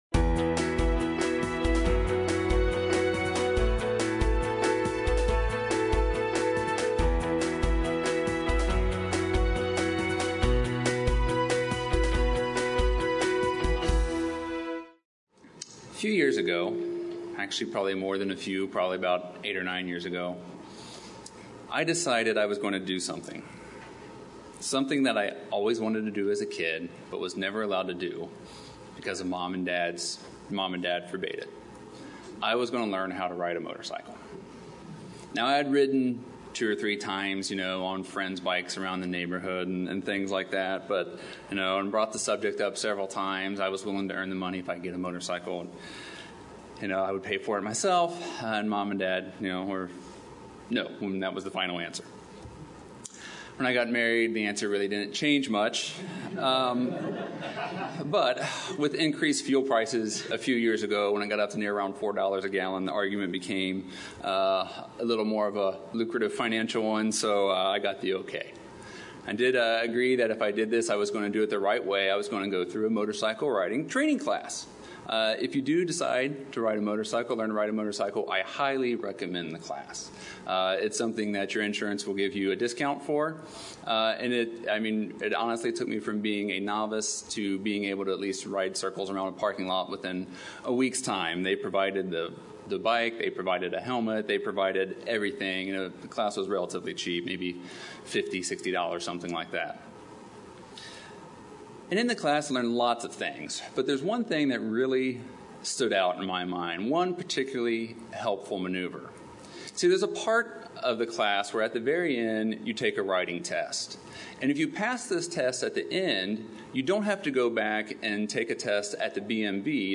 This message challenges you to set a physical and a spiritual goal this summer.